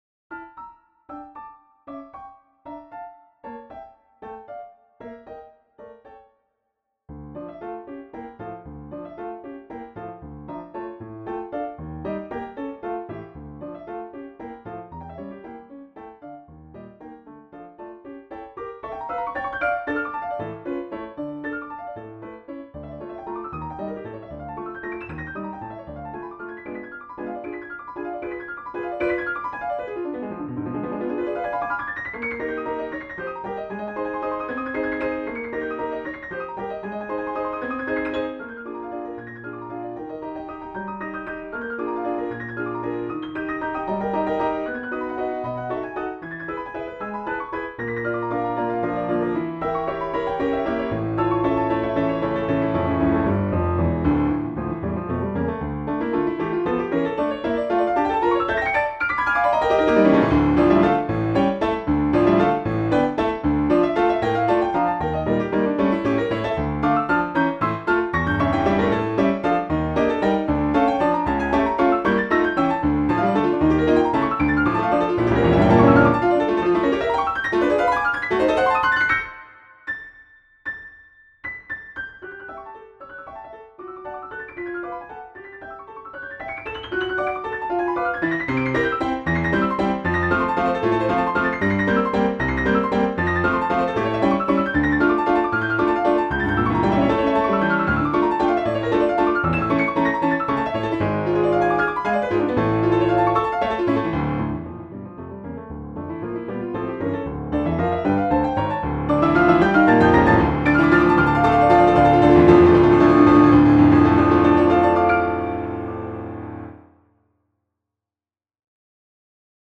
Étude Scintillante - Piano Music, Solo Keyboard
I wrote this one back in 2014 while exploring triplets in waltz form.